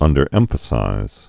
(ŭndər-ĕmfə-sīz)